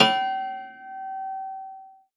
53w-pno07-G3.wav